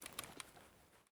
EFT Aim Rattle / gamedata / sounds / weapons / rattle / raise / raise_2.ogg